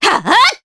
Scarlet-Vox_Attack4_Jp.wav